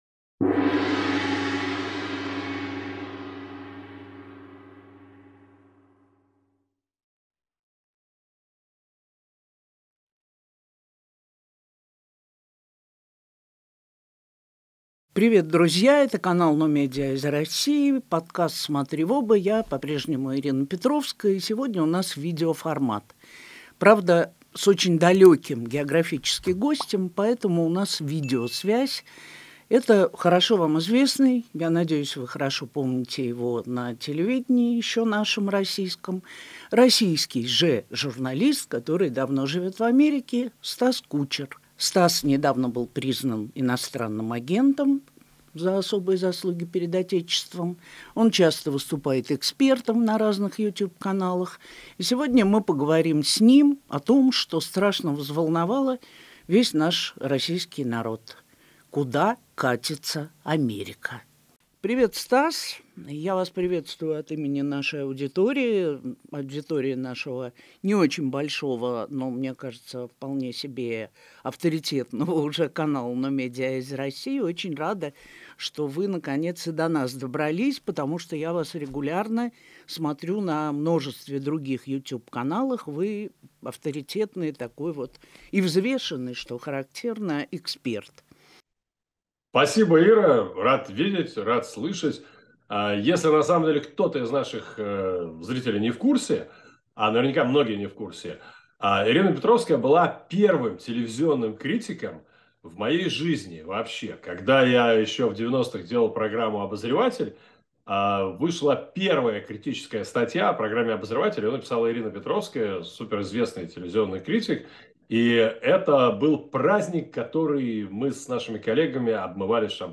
Поэтому у нас видеосвязь.